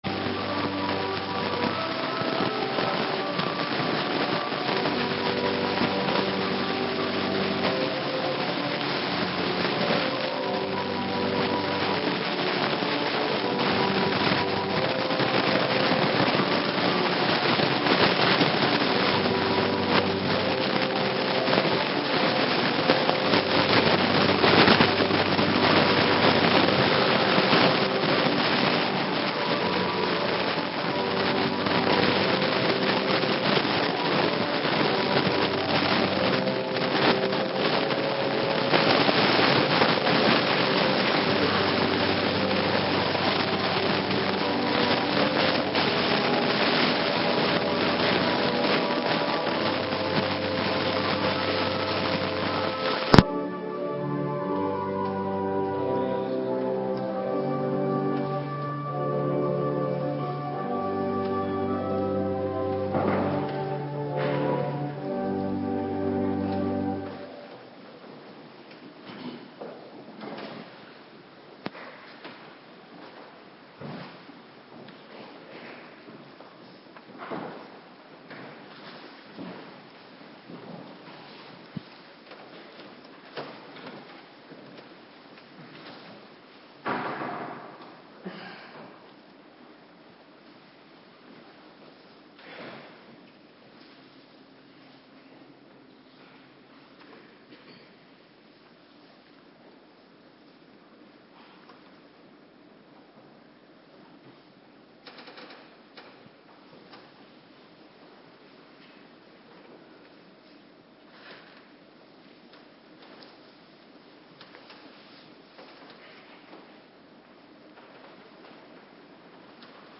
Morgendienst - Cluster 2
Locatie: Hervormde Gemeente Waarder